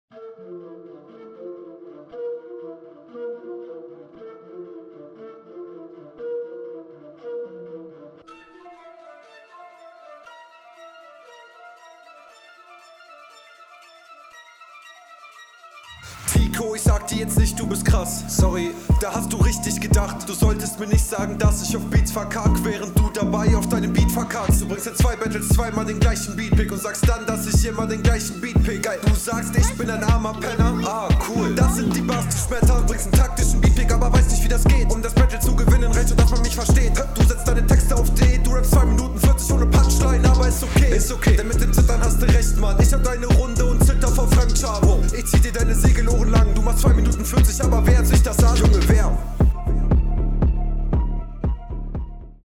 Soundqualität glücklicherweise auch wieder besser als zuvor.
mix ist nicht so schön wie beim front. text ist klar überlegen aber das war …